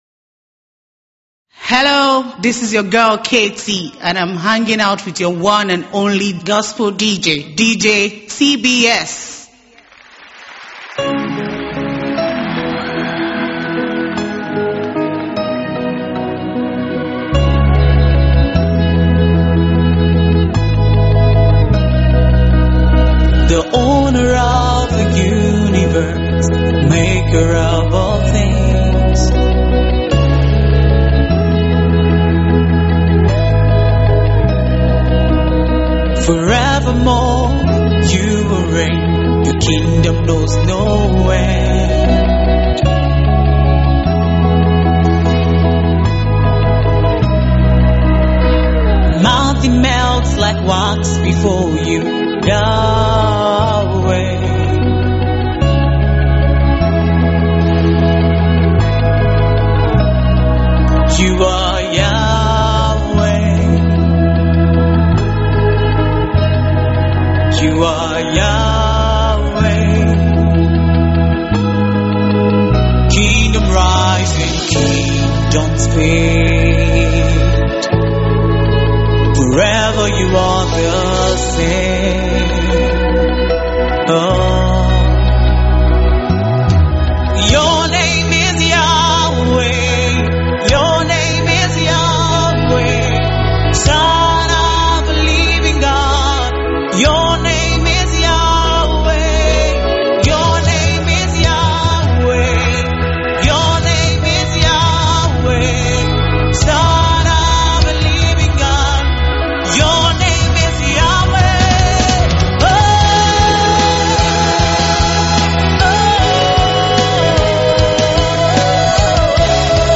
gospel DJ